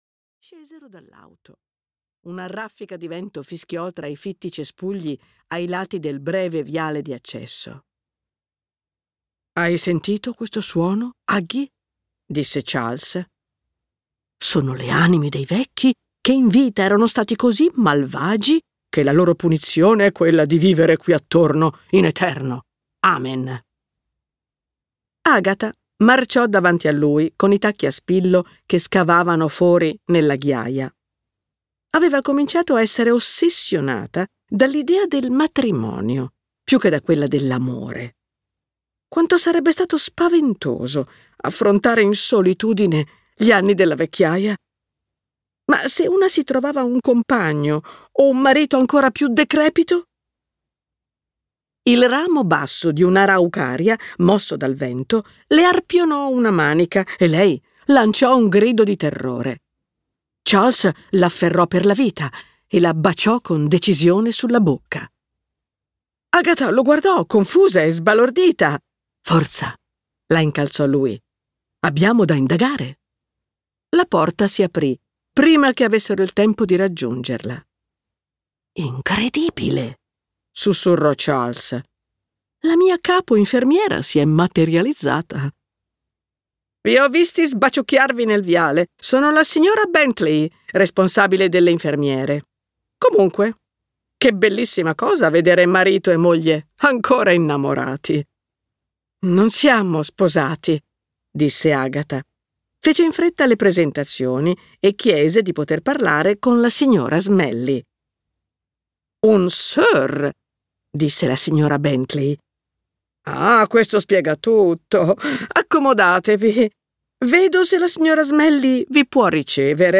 L'albero delle streghe" di M.C. Beaton - Audiolibro digitale - AUDIOLIBRI LIQUIDI - Il Libraio